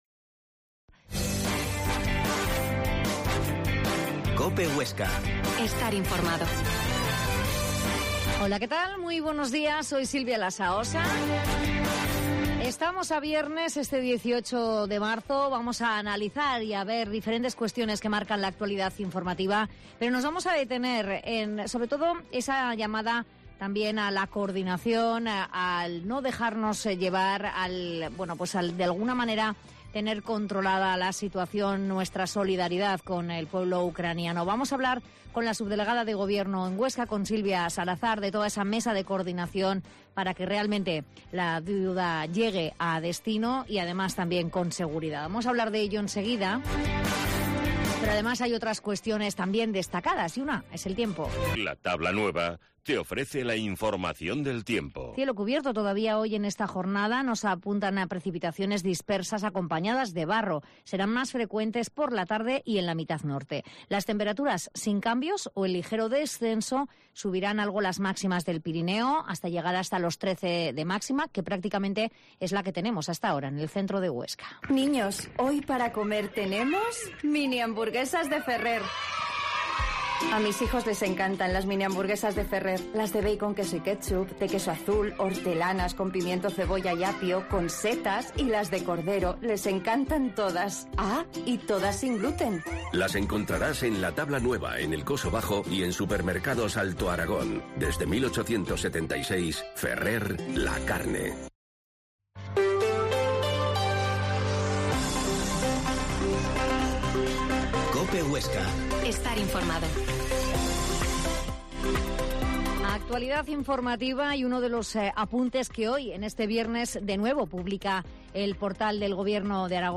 Herrera en COPE Huesca 12.50h Entrevista a la subdelegada de gobierno, Silvia Salazar